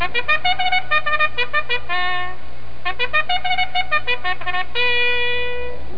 sounds / animals / horse2.wav
horse2.mp3